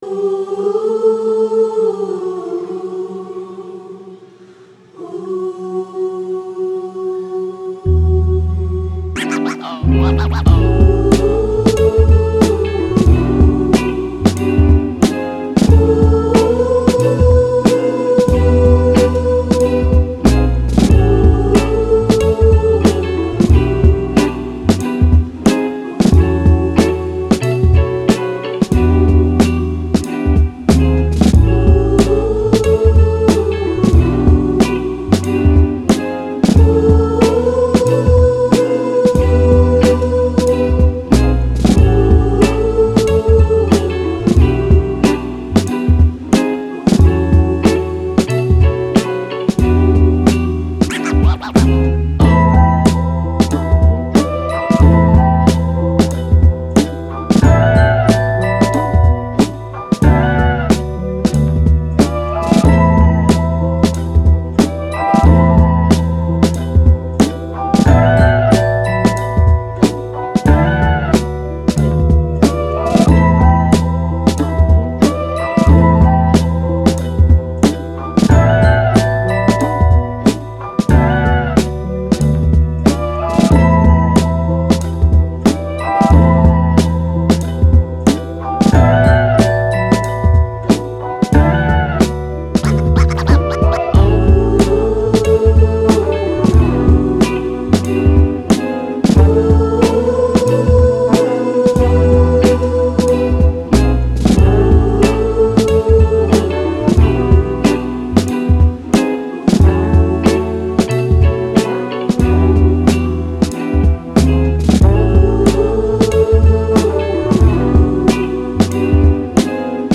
Soul, Hip Hop, Vintage, Chilled, Vibe